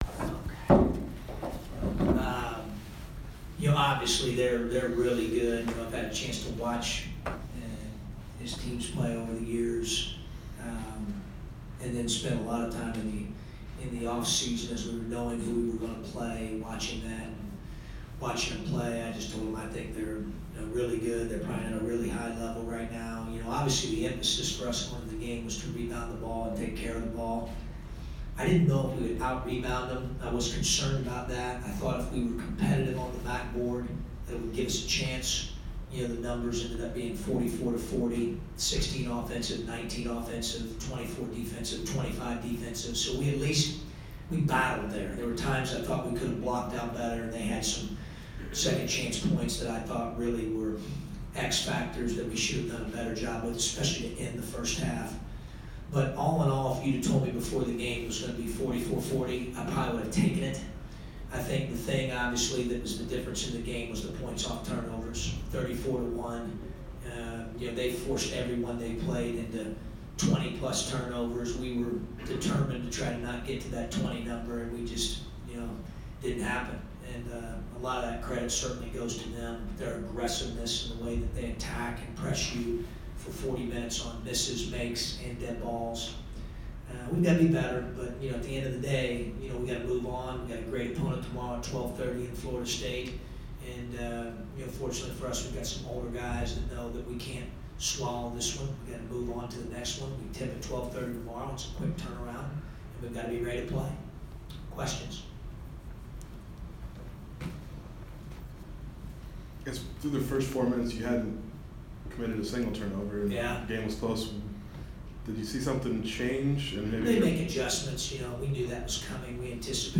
presser